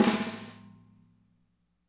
-MEDSNR3C -L.wav